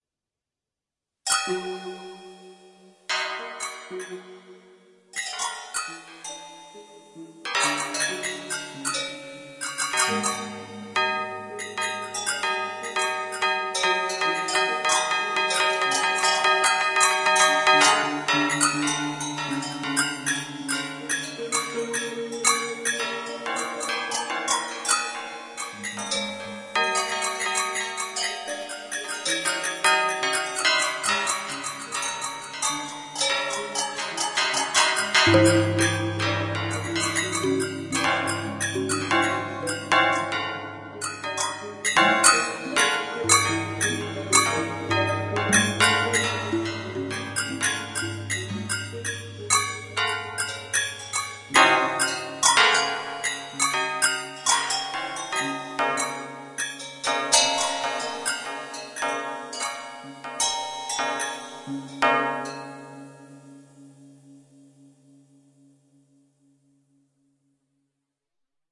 描述：电子钟